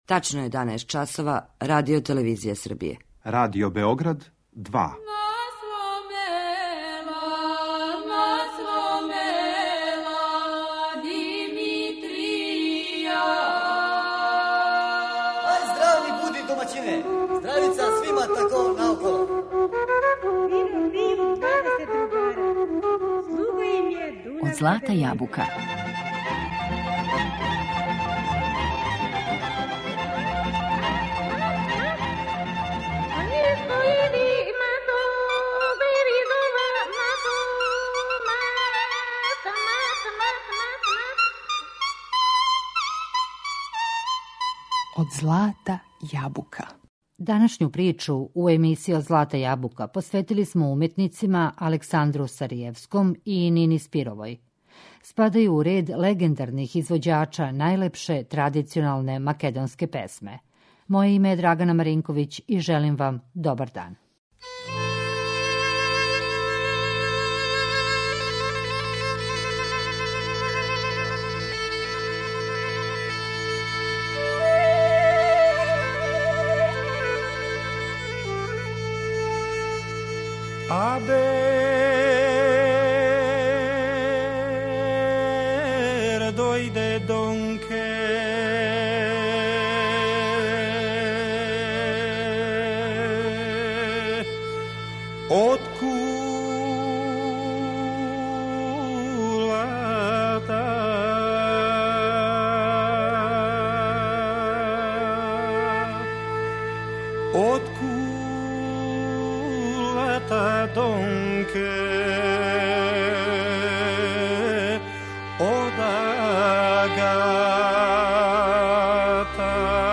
Традиционална македонска народна песма